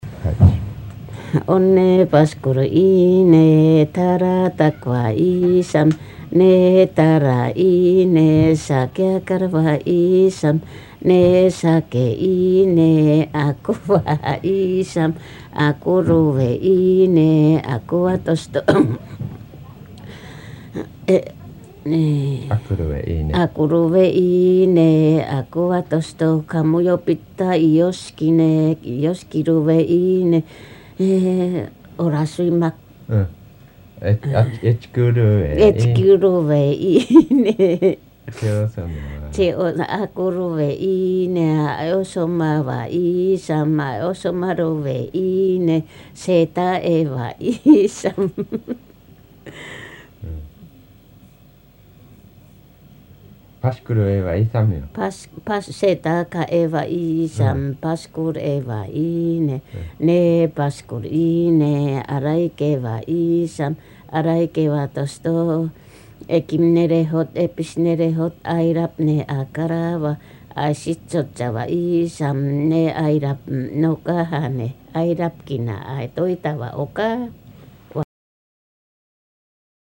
[4-6 言葉遊び wordplay] アイヌ語音声 1:19